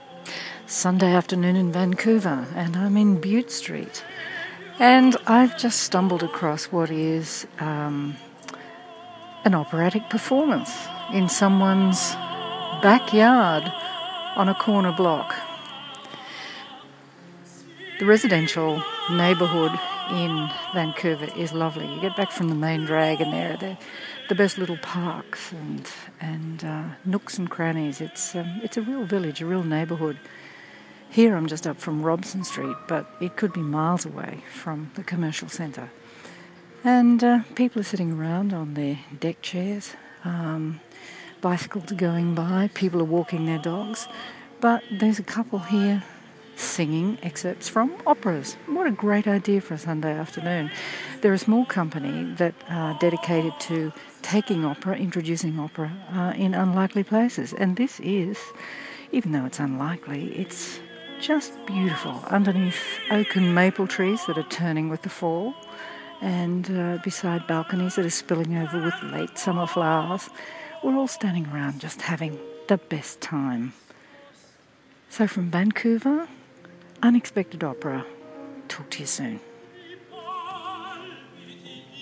Unexpected Kerbside Opera